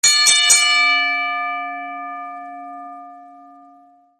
Wrestling Bell Sound Button - Free Download & Play